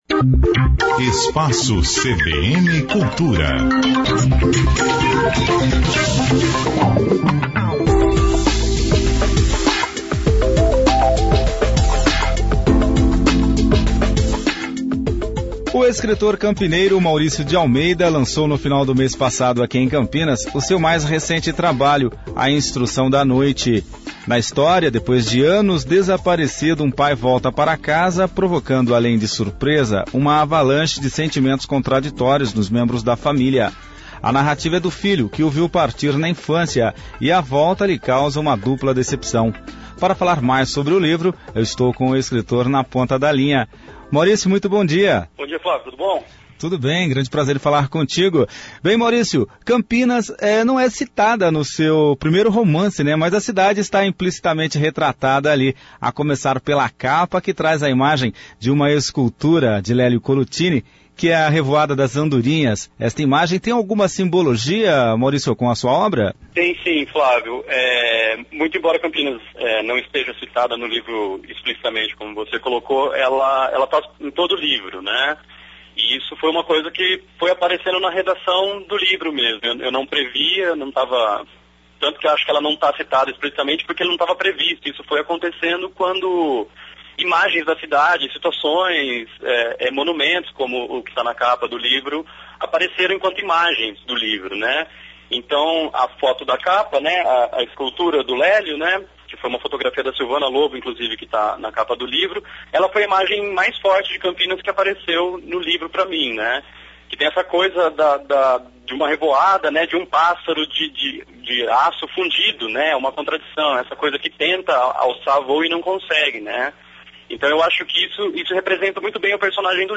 Essa entrevista foi ao ar no dia 12 de Março de 2016……